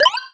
skill_used_01.wav